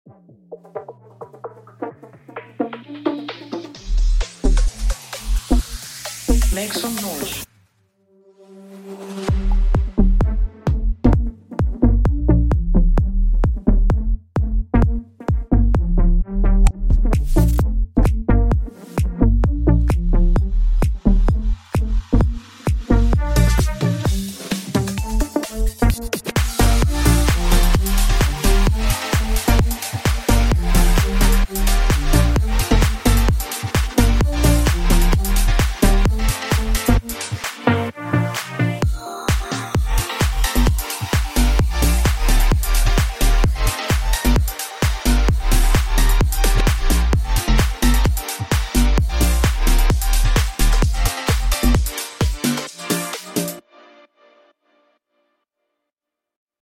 HOUSE.wav מבחינת המיקס זה ממש השלב ההתחלתי אבל אהבתי את העומק אגב אתם לא מאמינים איזה מקבצים אני בונה על הGENOS עם הסט החדש שלי אתם נופלים מהרגליים ברוך ה’ זה מתקדם יפה